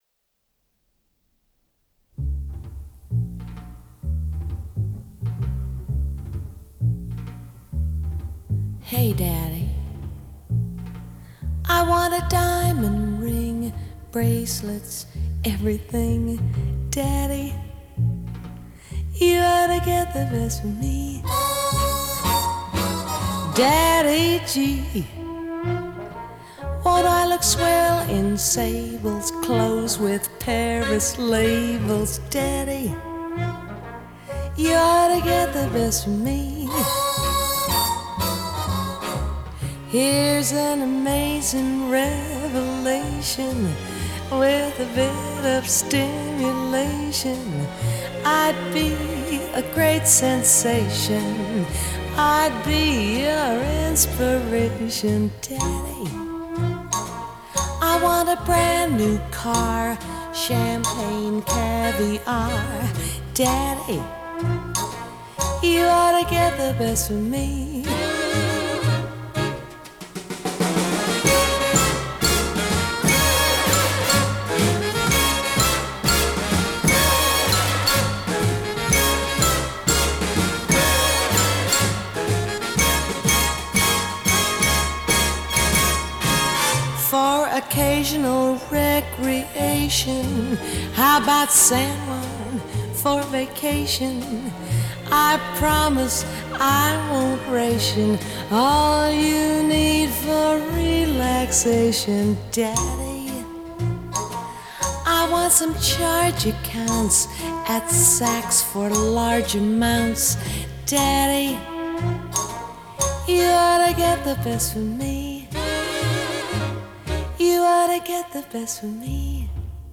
Recorded at the Sofiensaal in Vienna.